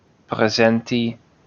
wymowa: